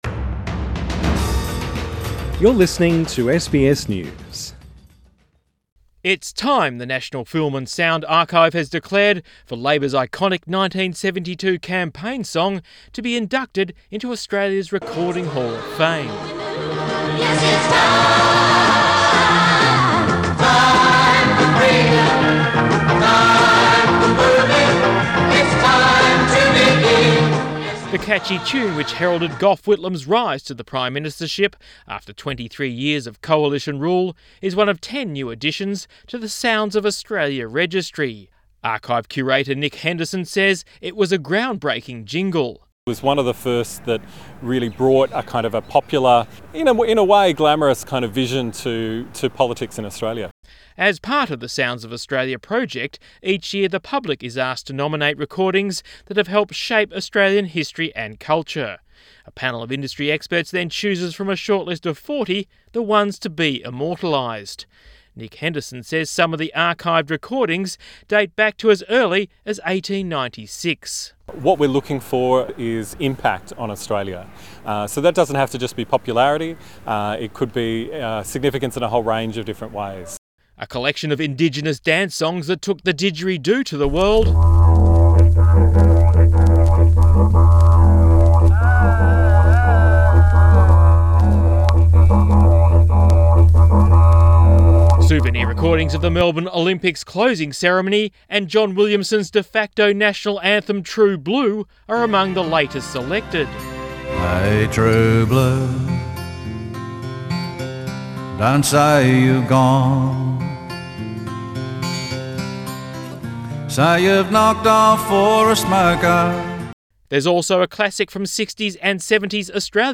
WARNING: Aboriginal and Torres Strait Islander viewers are advised that the following item may contain audio of deceased persons Share